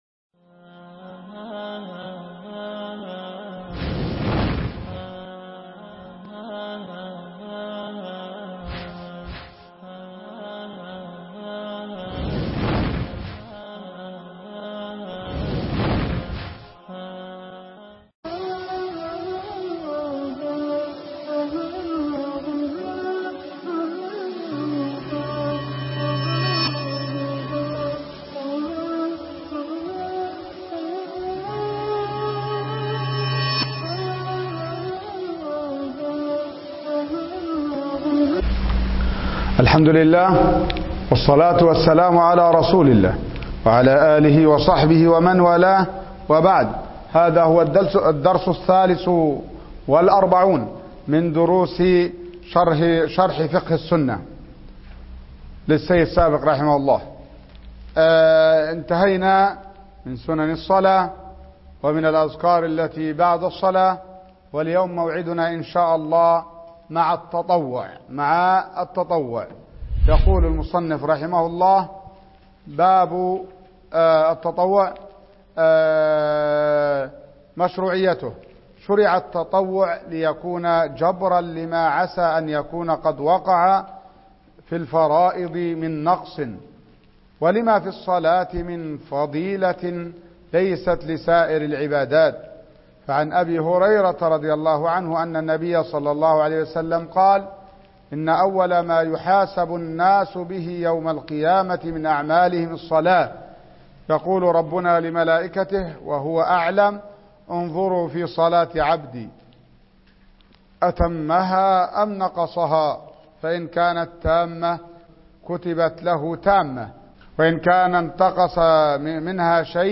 شرح كتاب فقه السنة الدرس 43